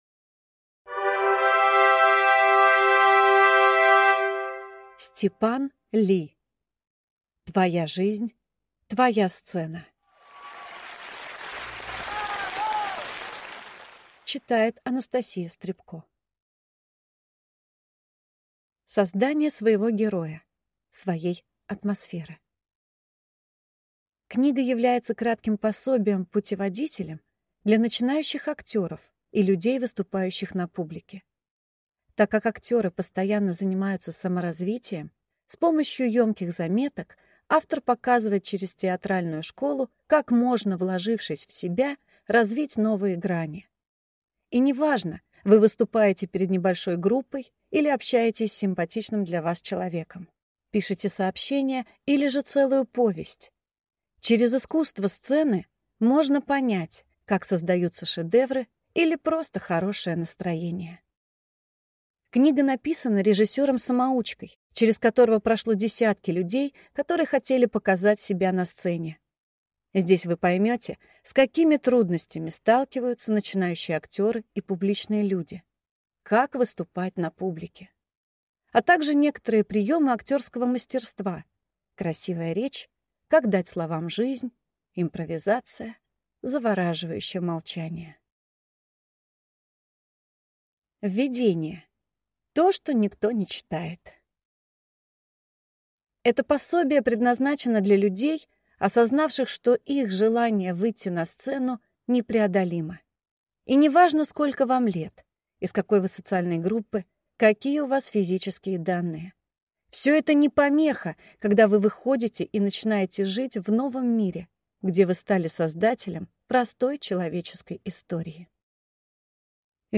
Аудиокнига Твоя жизнь – твоя сцена | Библиотека аудиокниг